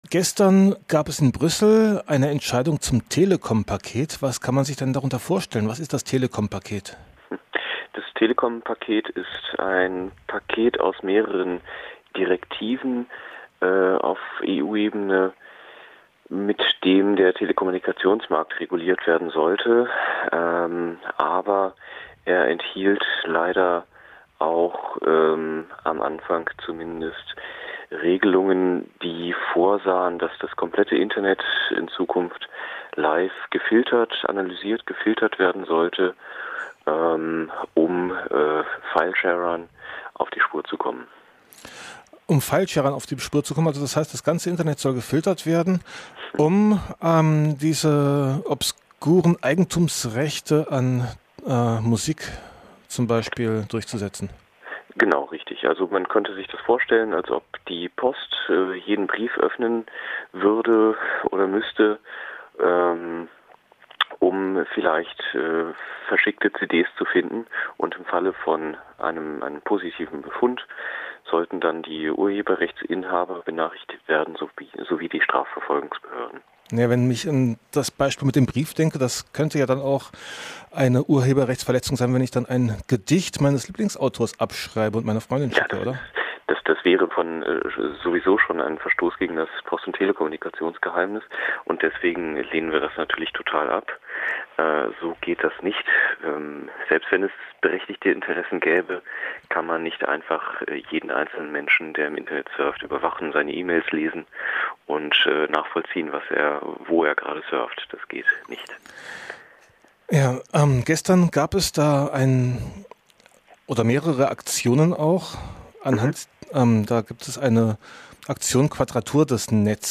Interview als MP3-Datei (ca. 11,5 MB)